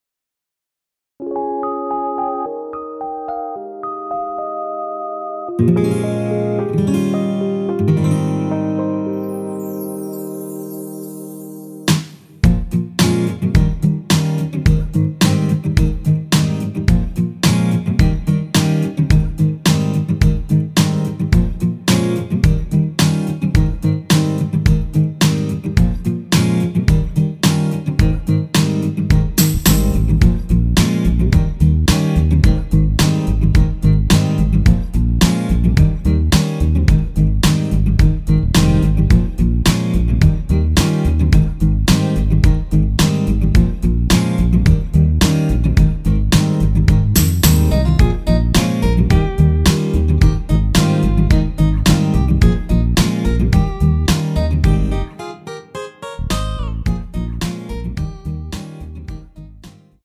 원키에서 (-1)내린 MR입니다.
Ab
앞부분30초, 뒷부분30초씩 편집해서 올려 드리고 있습니다.